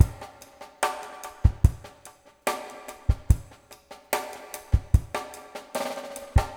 BOL DRMWET-R.wav